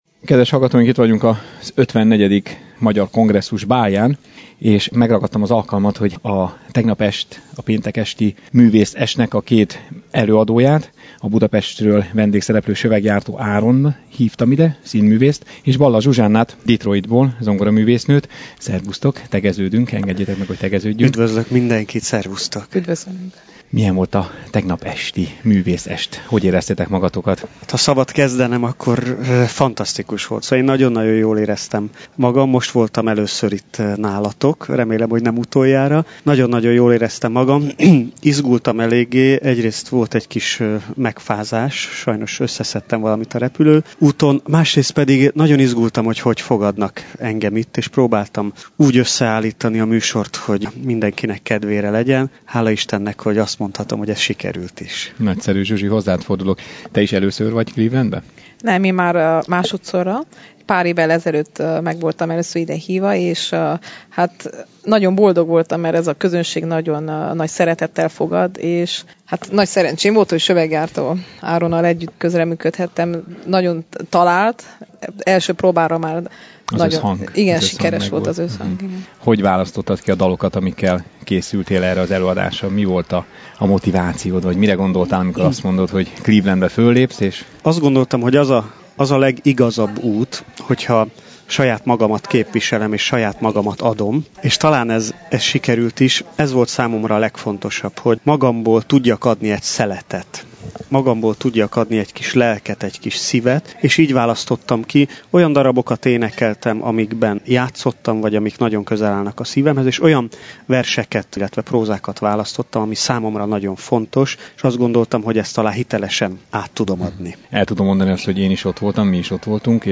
A Bocskai Rádió munkatársai is állva tapsoltuk meg őket majd leültem velük egy pár perc erejéig a mikrofonok elé, ahol megosztották tapasztalataikat és a hallgatók is megismerhették őket.